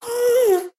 Minecraft Version Minecraft Version snapshot Latest Release | Latest Snapshot snapshot / assets / minecraft / sounds / mob / ghast / moan3.ogg Compare With Compare With Latest Release | Latest Snapshot
moan3.ogg